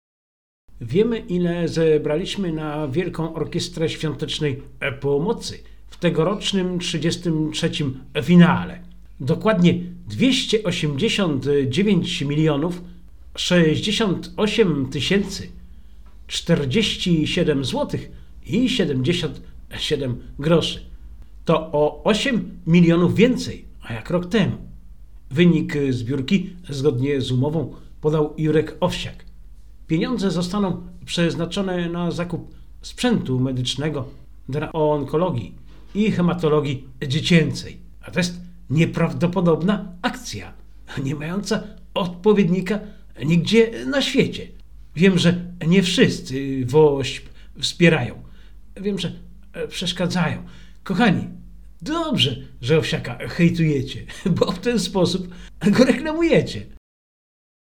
A oto wydarzenia z ostatniego tygodnia, które zauważyłem i w Radiu MARA omówiłem.